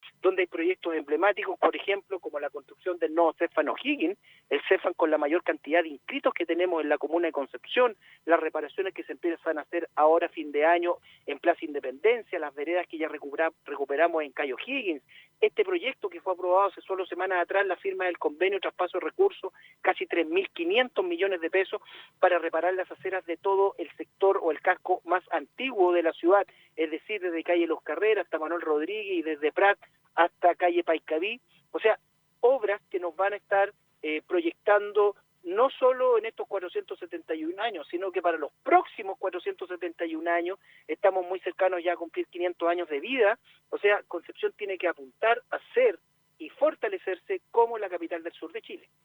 En entrevista con Radio UdeC, Álvaro Ortiz, alcalde de Concepción, comentó que «los proyecto no sólo deben ser en beneficio de quienes vivimos en el territorio, sino que también tenemos que pensar que somos parte de una zona metropolitana, donde habitamos más de un millón de personas», agregando que la ciudad «tiene que apostar a ser y fortalecerse como la capital del sur de Chile».